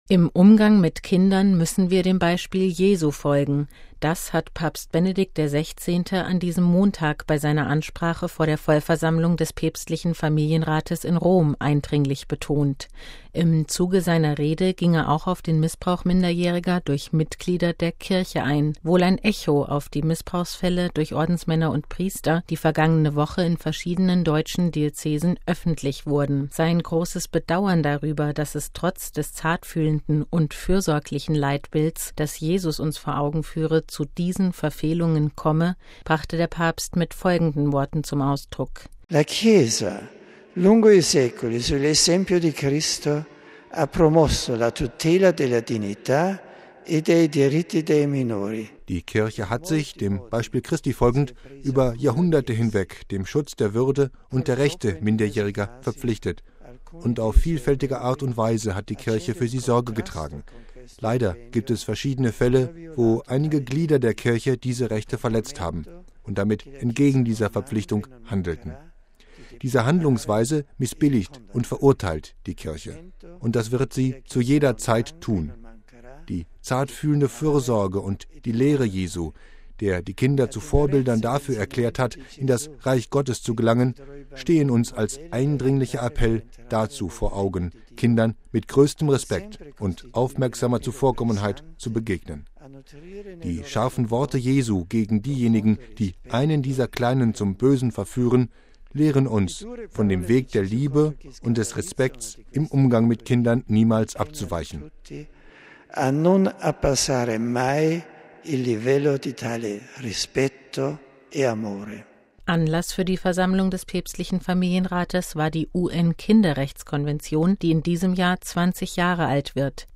MP3 Im Umgang mit Kindern müssen wir dem Beispiel Jesu folgen. Das hat Papst Benedikt XVI. an diesem Montag bei seiner Ansprache vor der Vollversammlung des päpstlichen Familienrates in Rom eindringlich betont.